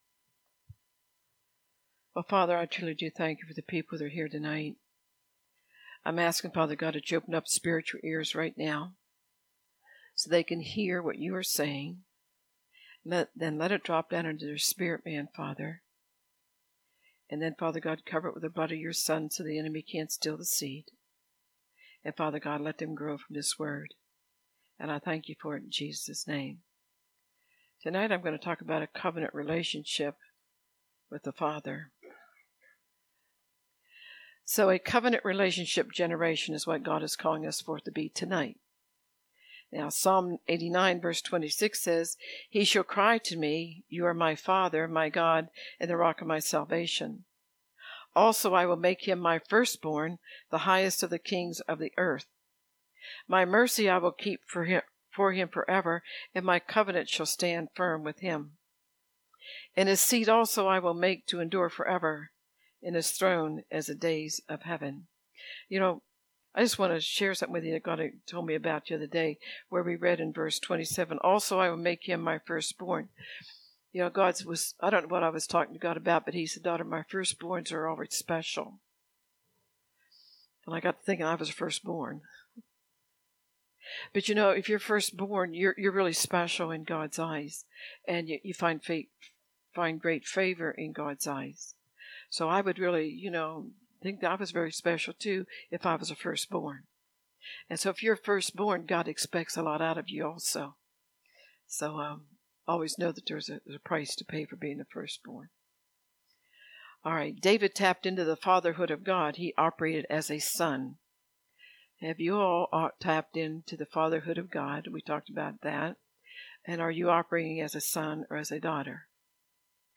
In His Presence Revival